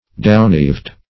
Search Result for " downgyved" : The Collaborative International Dictionary of English v.0.48: Downgyved \Down"gyved`\, a. Hanging down like gyves or fetters.